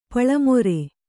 ♪ paḷa more